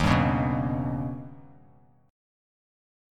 Eb+7 chord